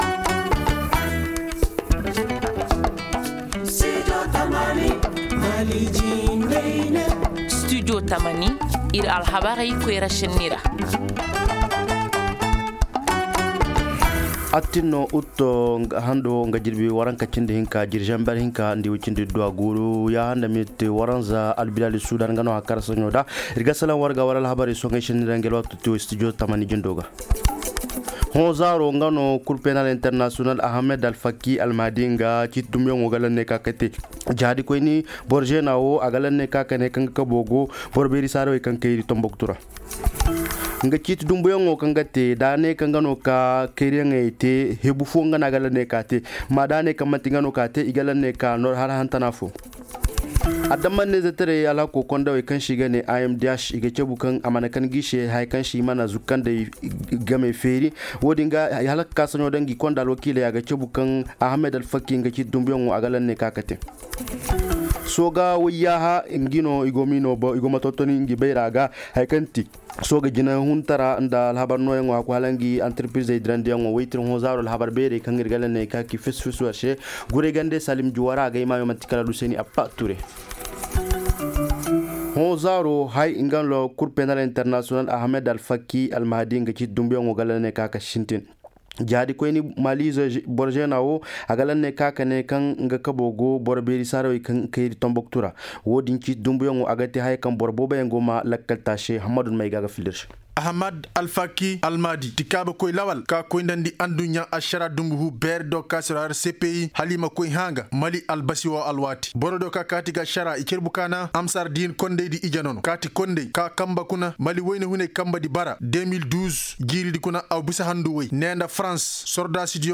Ci-dessous, écoutez le développement de ces titres dans nos journaux en français et en langues nationales :